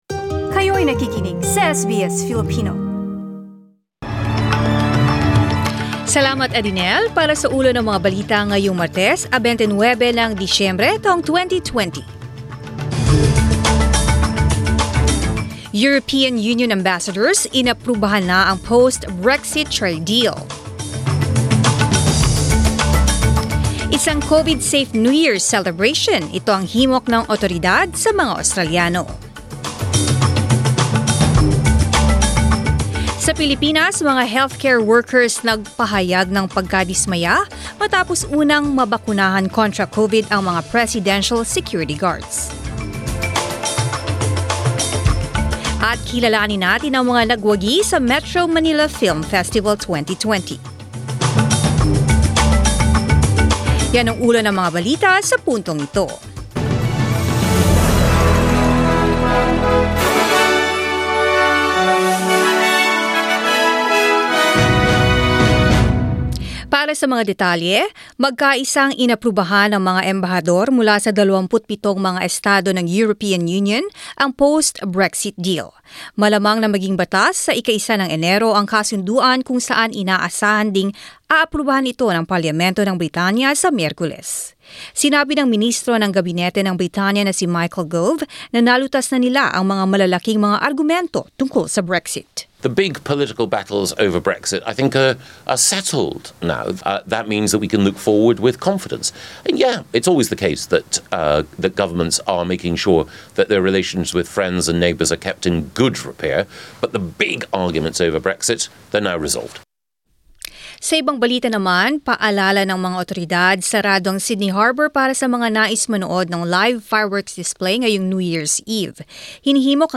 SBS News in Filipino, 29 December